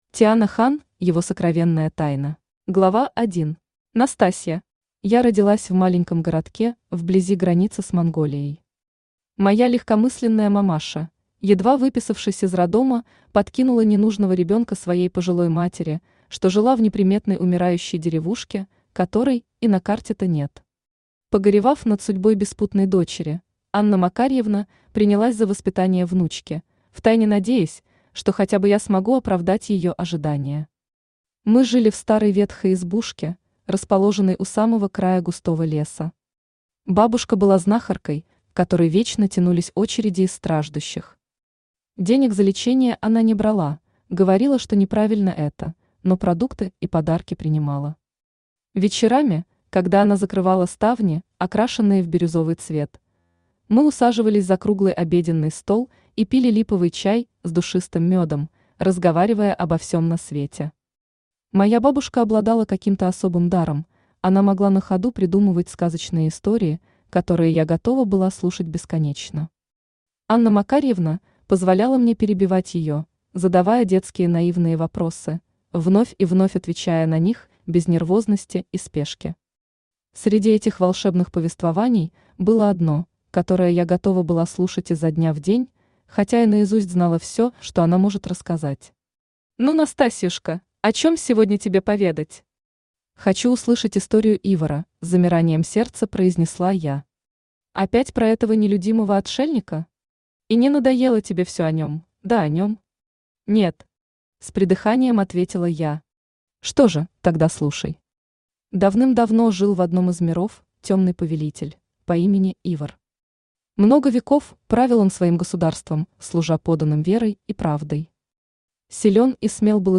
Аудиокнига Его сокровенная тайна | Библиотека аудиокниг
Aудиокнига Его сокровенная тайна Автор Тиана Хан Читает аудиокнигу Авточтец ЛитРес.